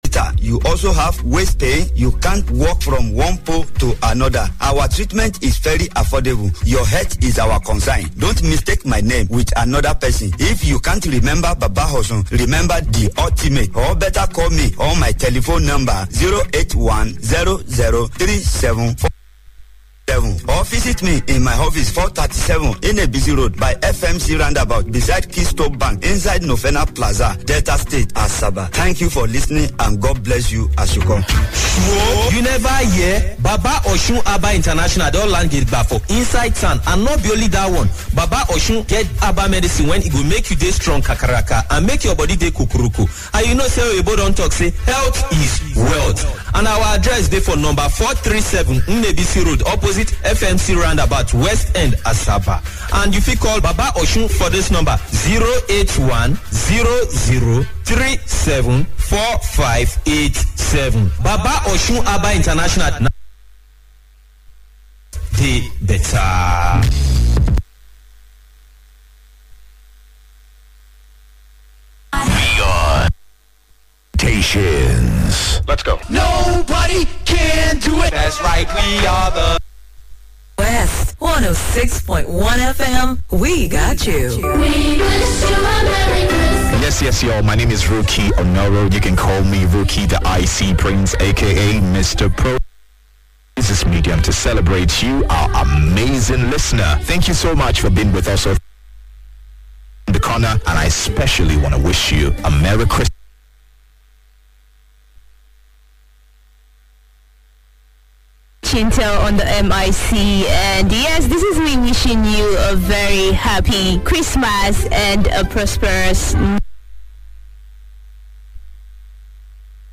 this is one of my lovely shows in Asaba